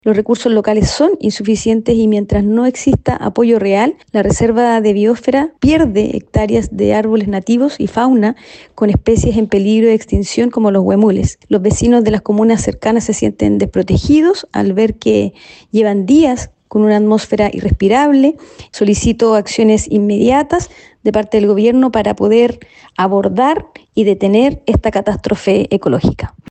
Ante esto, la diputada Marta Bravo solicitó al Gobierno acciones inmediatas ante lo que denominó como una catástrofe ecológica.